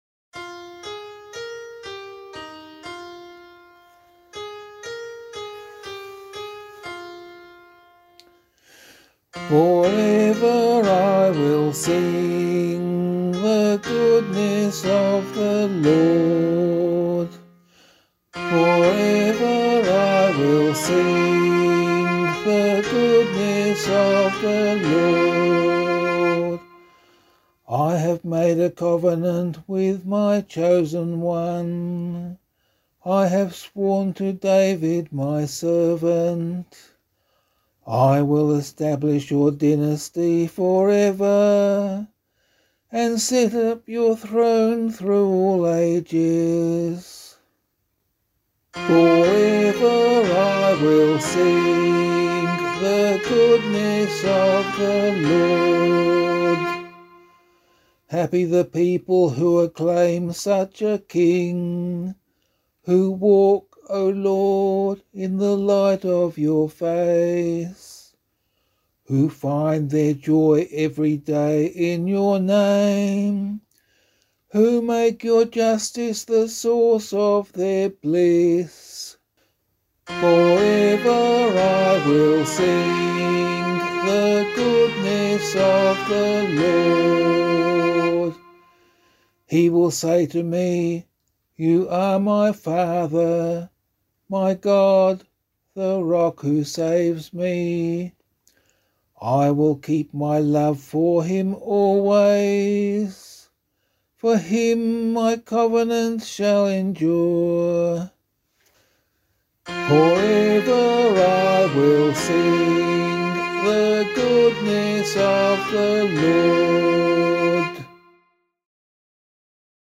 005 Christmas Vigil Psalm [LiturgyShare 2 - Oz] - vocal.mp3